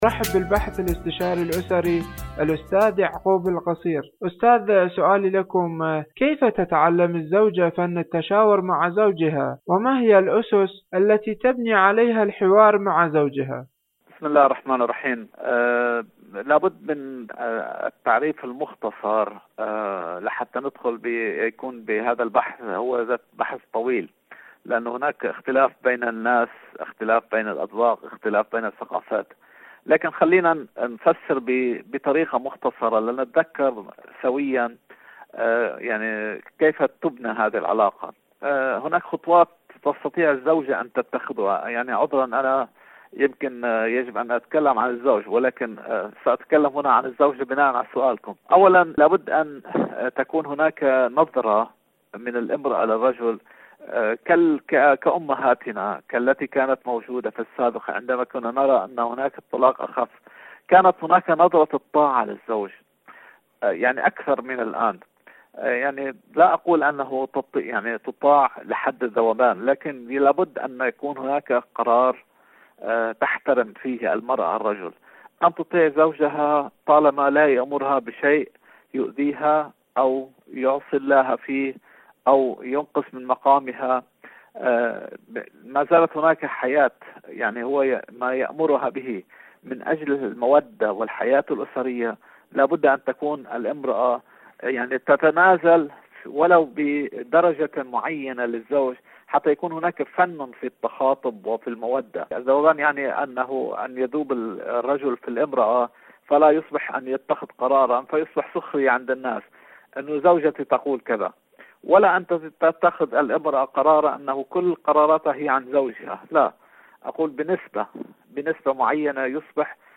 إذاعة طهران - عالم المرأة: مقابلة إذاعية